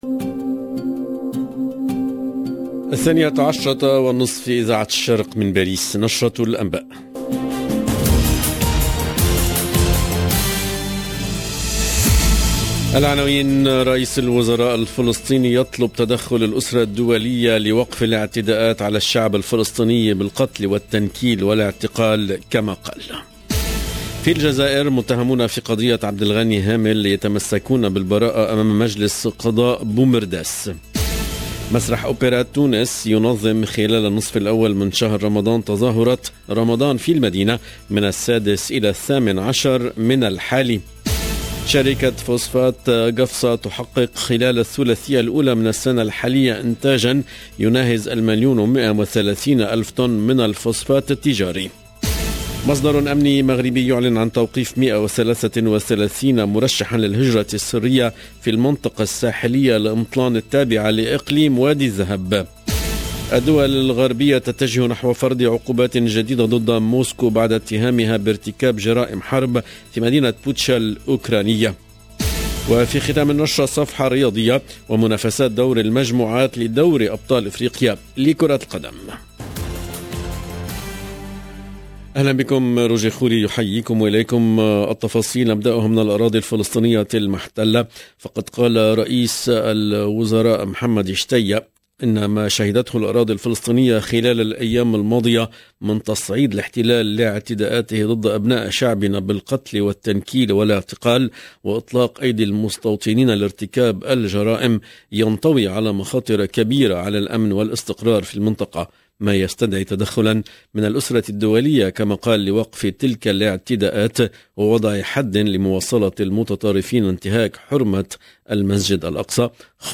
LE JOURNAL DE MIDI 30 EN LANGUE ARABE DU 4/04/22